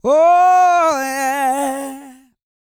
E-GOSPEL 212.wav